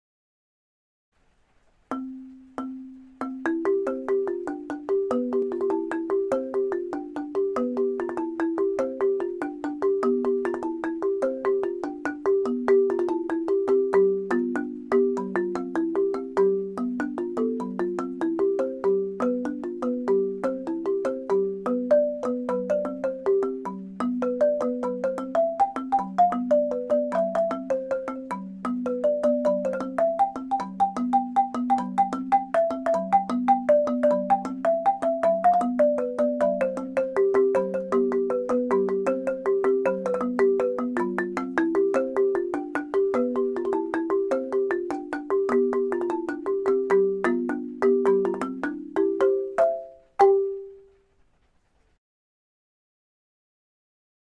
Marimbas_de_chonta_Rio.mp3